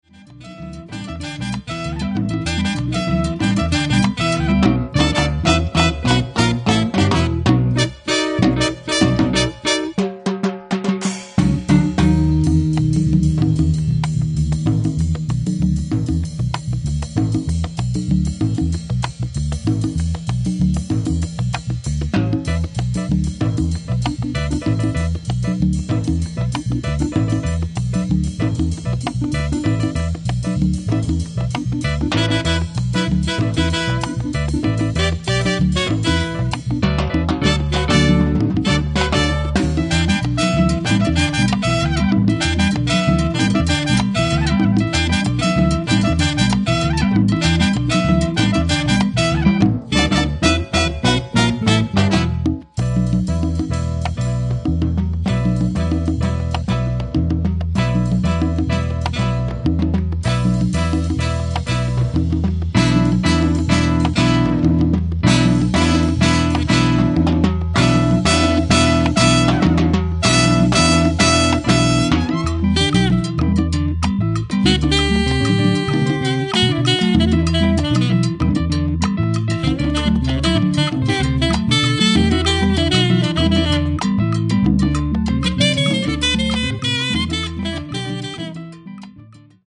Enregistré au Studio Gam à Waimes (Belgique)